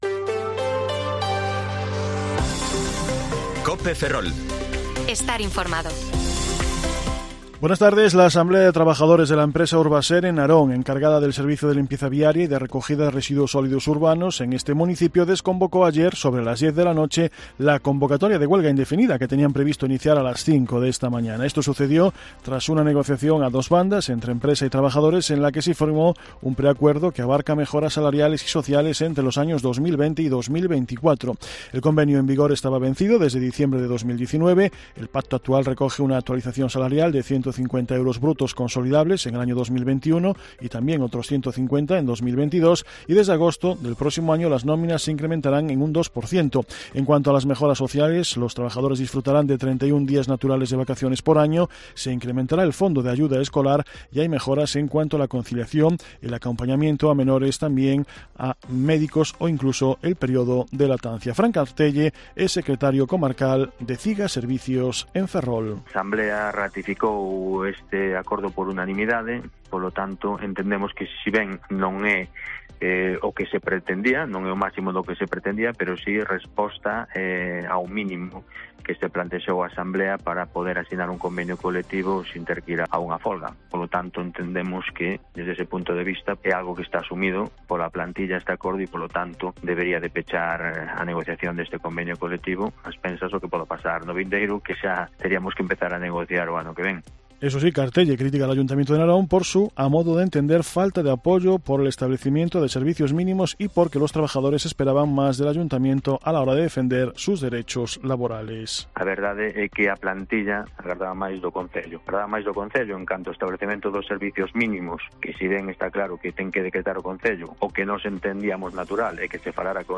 Informativo Mediodía COPE Ferrol 25/04/2023 (De 14,20 a 14,30 horas)